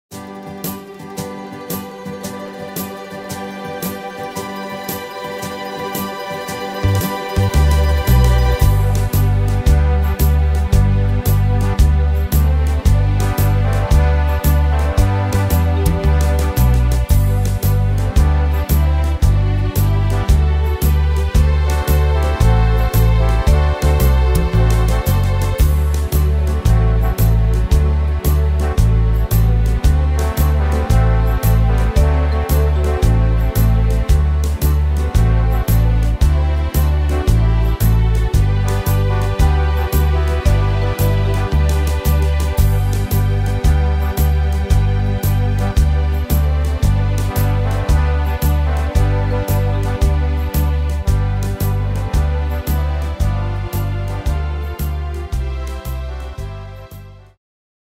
Tempo: 113 / Tonart: G-Dur